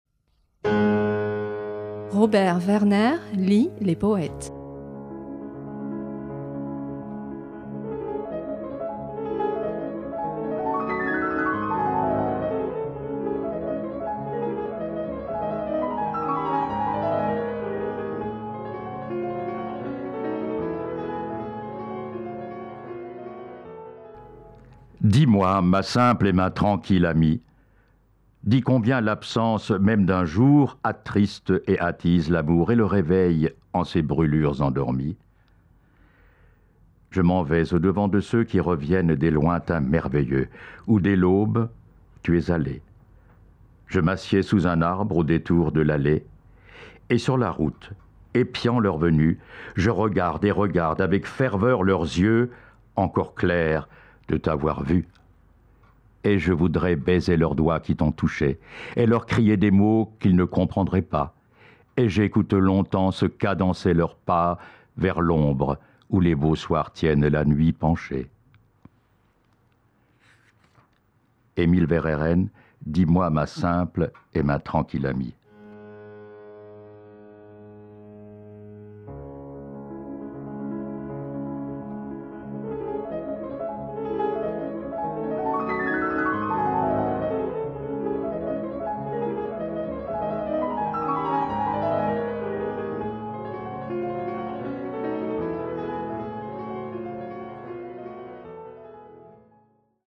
lit cette semaine Dis-moi, ma simple et ma tranquille amie, un poème d’Émile Verhaeren (1855-1916).